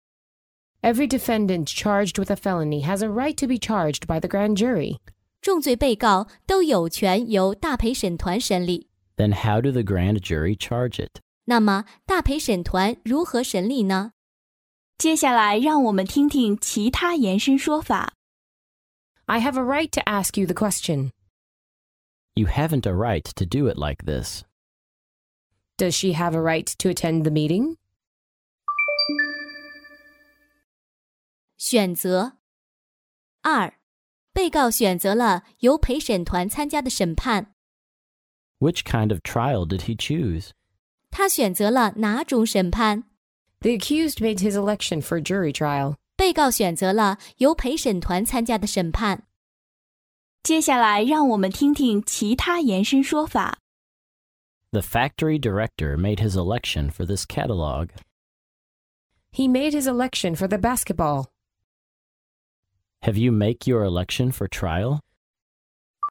在线英语听力室法律英语就该这么说 第21期:重罪被告知都有权由大陪审团审理的听力文件下载,《法律英语就该这么说》栏目收录各种特定情境中的常用法律英语。真人发音的朗读版帮助网友熟读熟记，在工作中举一反三，游刃有余。